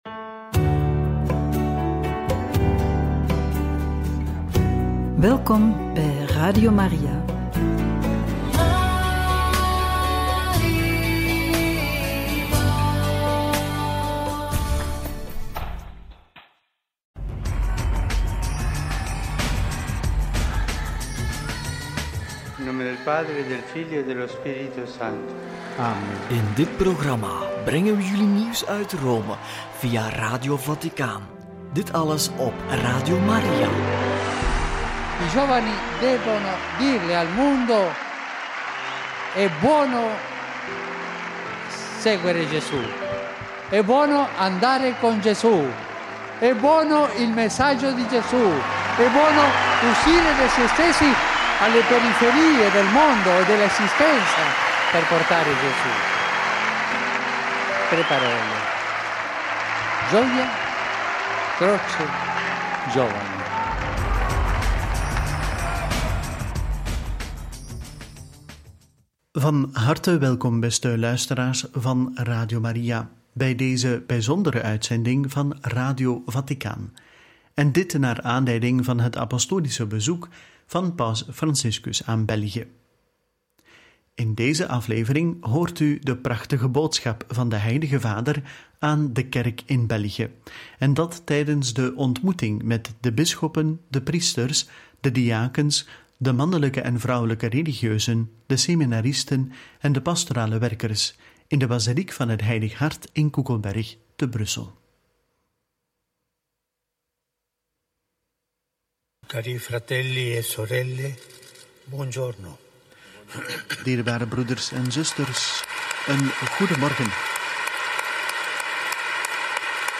28/9 Paus Franciscus ontmoet bisschoppen, priesters, diakens, religieuzen, seminaristen en pastorale werkers in de Basiliek van het Heilig Hart in Koekelberg – Radio Maria